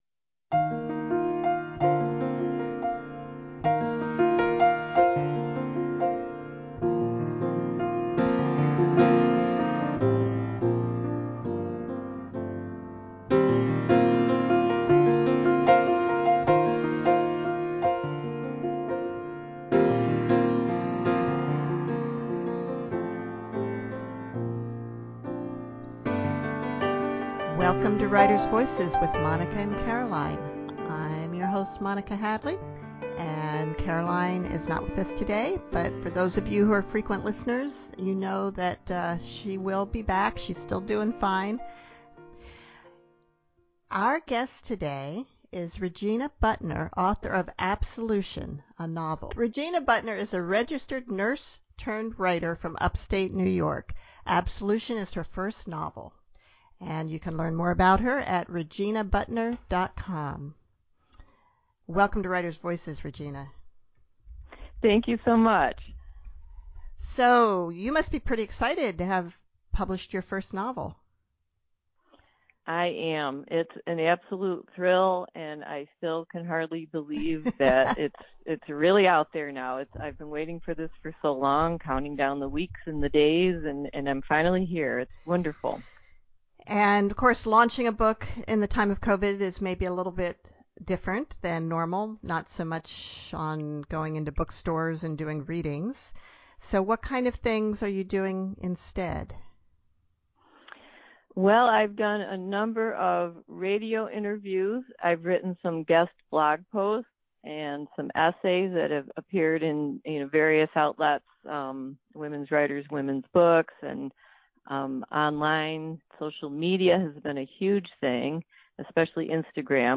Our conversation